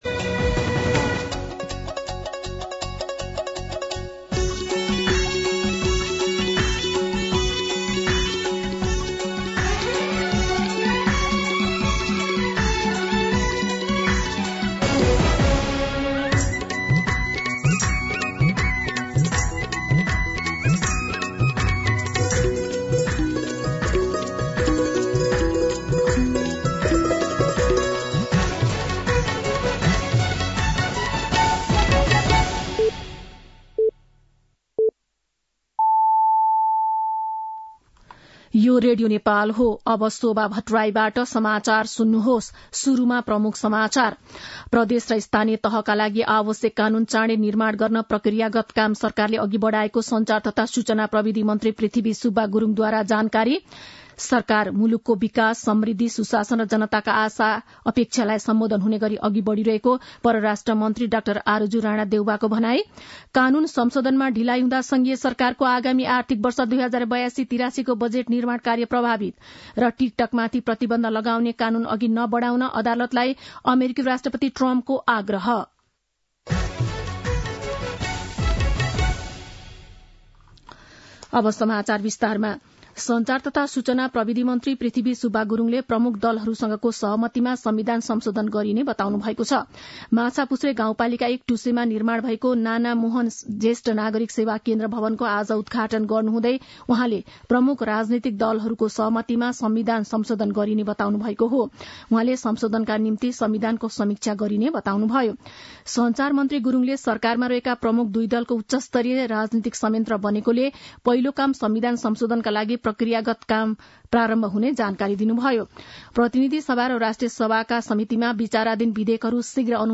दिउँसो ३ बजेको नेपाली समाचार : १४ पुष , २०८१
3-pm-Nepali-News-4.mp3